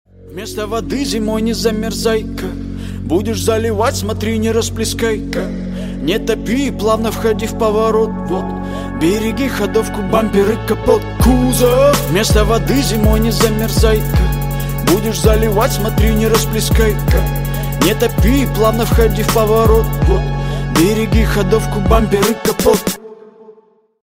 Кавер И Пародийные Рингтоны